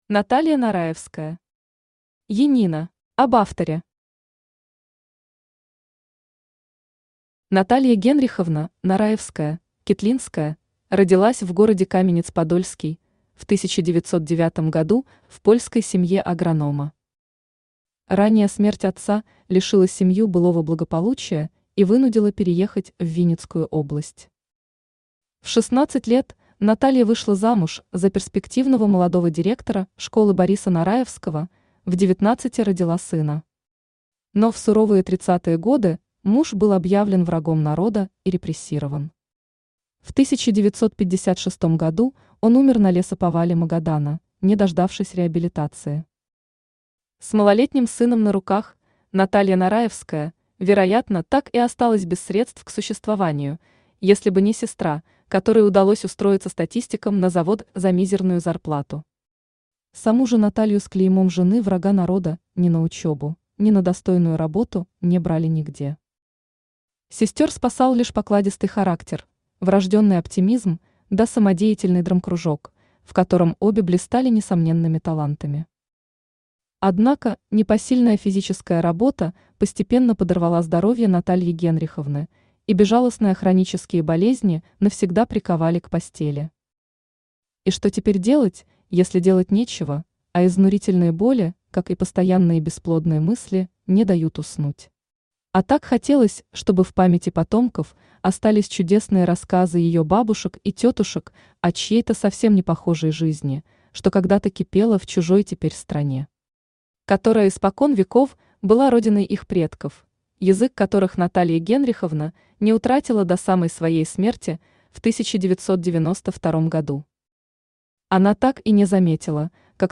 Aудиокнига Янина Автор Наталья Генриховна Нараевская Читает аудиокнигу Авточтец ЛитРес. Прослушать и бесплатно скачать фрагмент аудиокниги